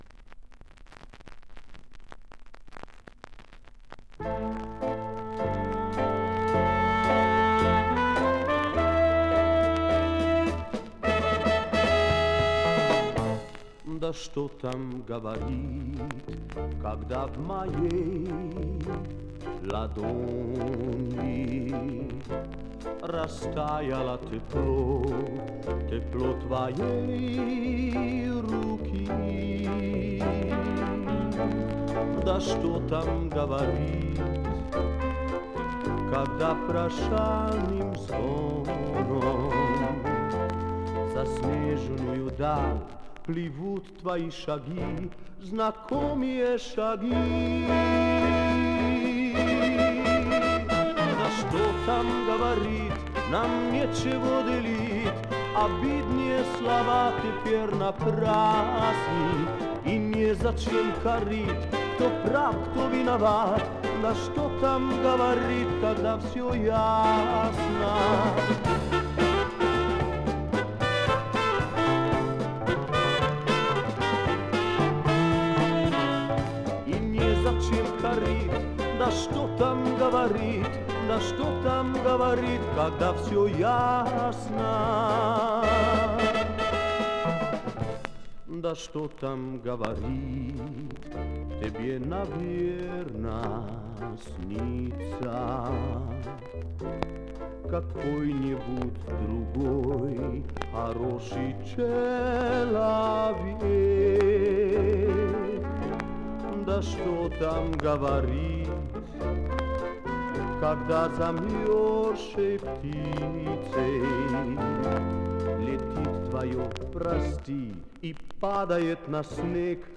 Мозилла и Макстон крутят нормально с раритетными скрипами.